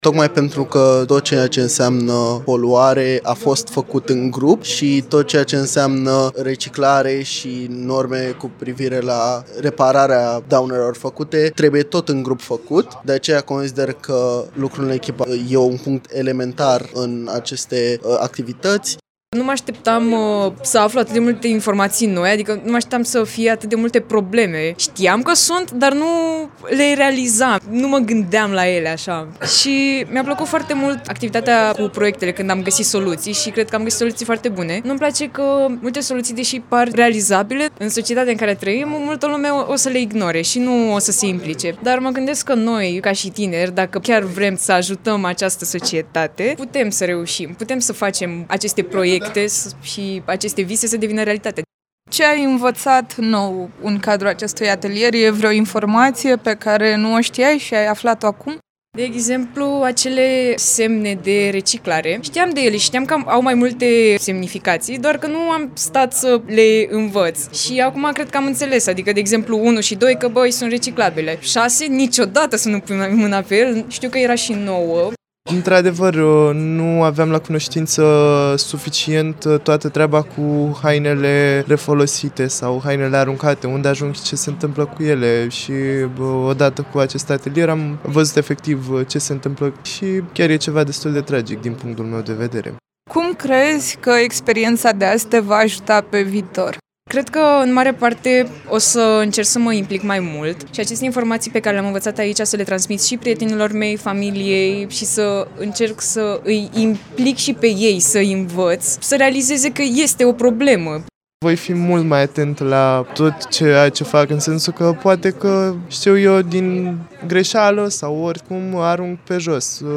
Iată câteva dintre impresiile lor, într-un reportaj